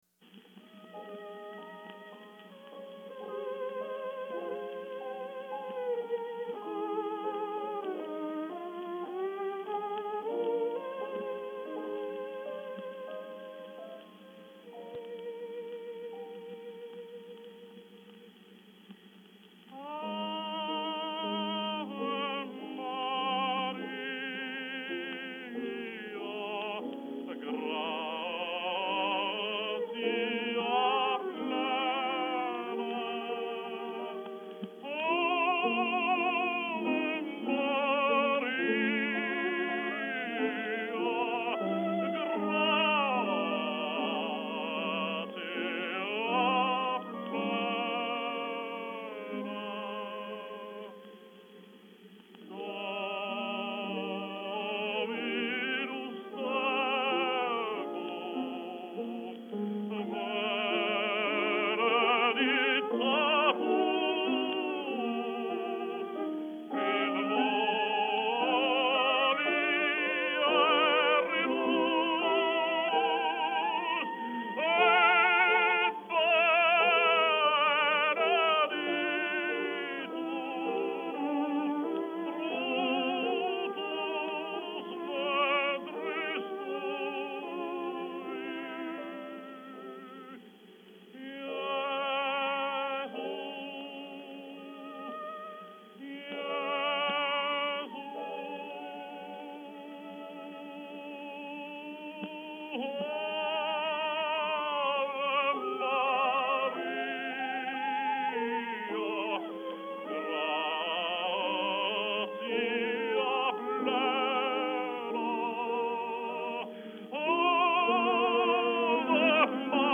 Жанр: Vocal
violin
piano